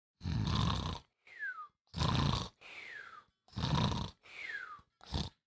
SNORING
cartoon sleeping snoring sound effect free sound royalty free Movies & TV